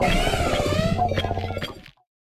Cri de Roue-de-Fer dans Pokémon Écarlate et Violet.